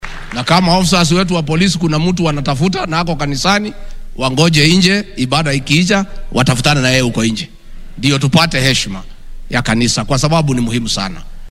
Arrintan ayuu shalay ka sheegay deegaanka Kandara ee ismaamulka Muranga. Gachagua ayaa hoosta ka xarriiqay in saraakiisha booliska laga doonayo inay bannaanka ku sugaan qofka ay raadinayaan.